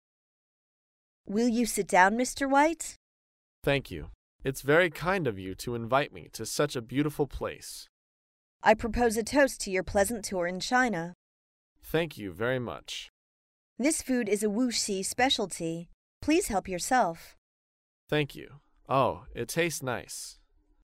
在线英语听力室高频英语口语对话 第356期:招待外宾的听力文件下载,《高频英语口语对话》栏目包含了日常生活中经常使用的英语情景对话，是学习英语口语，能够帮助英语爱好者在听英语对话的过程中，积累英语口语习语知识，提高英语听说水平，并通过栏目中的中英文字幕和音频MP3文件，提高英语语感。